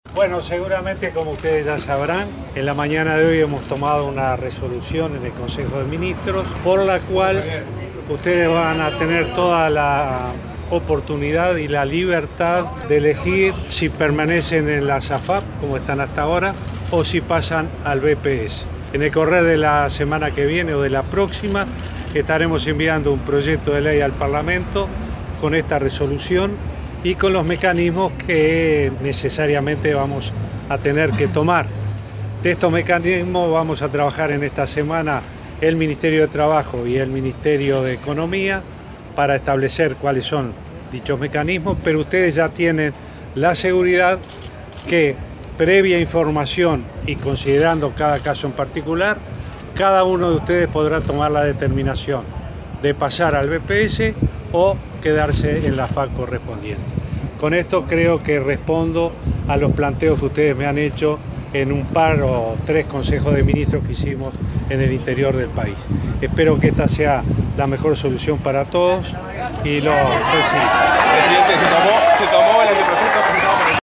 El presidente Vázquez informó de primera mano los términos de la resolución del Gobierno de este lunes sobre seguridad social, a un grupo de personas de los denominados “cincuentones” que se concentraron frente a Torre Ejecutiva. “Ustedes ya tienen la seguridad de que, previa información y consideración d cada caso en particular, podrán tomar la determinación que deseen”, acotó lo que provocó el aplauso de los manifestantes.